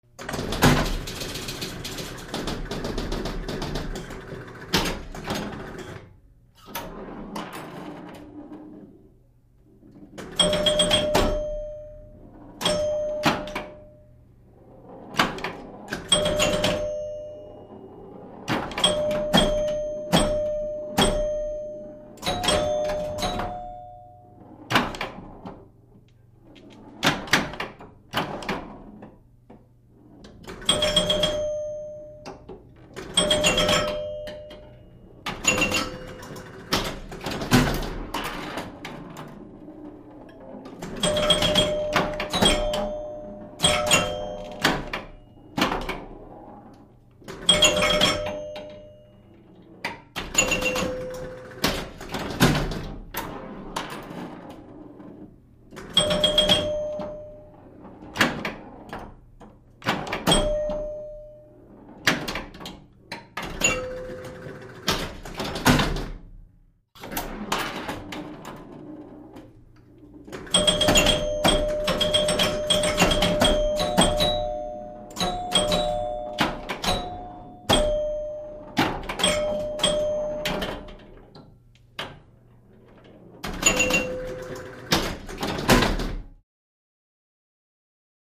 Pinball Machine; On With New Game Ball Thump, Play Various Game Balls With Close Up Bells And Hits, Off